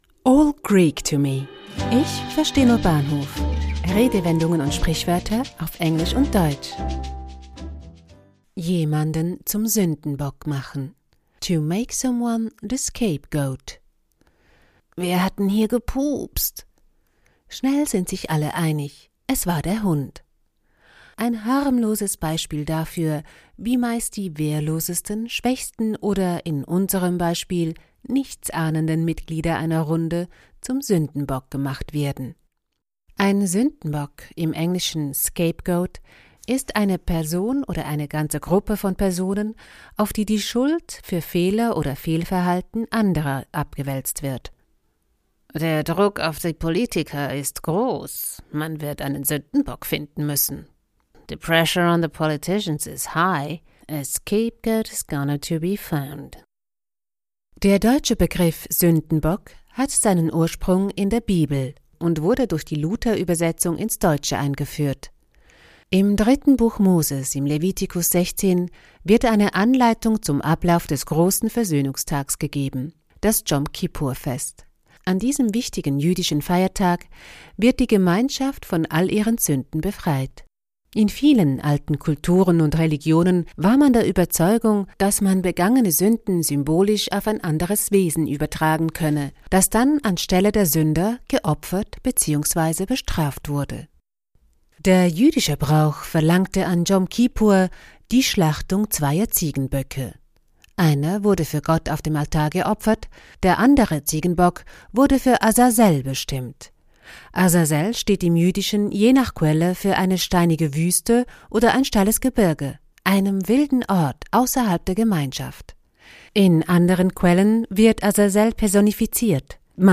Sprecherin: